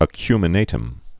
(ə-kymə-nātəm)